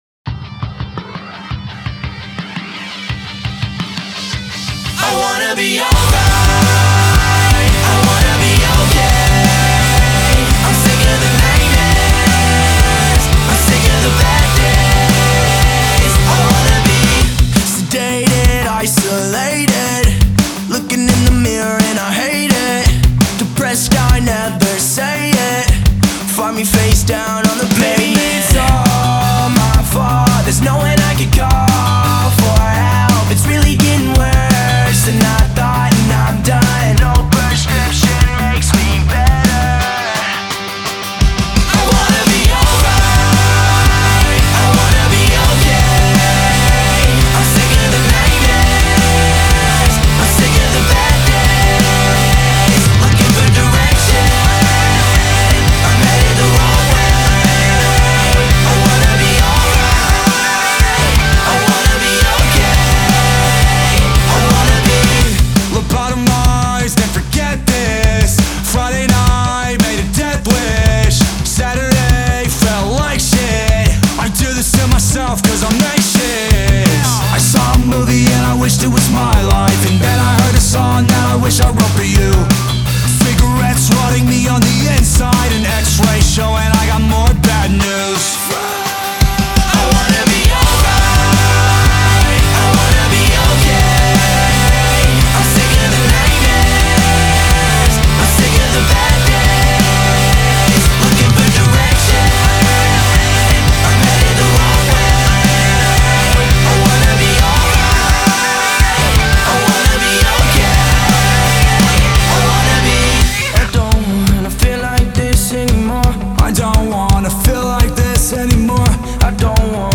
это энергичная песня в жанре поп-панк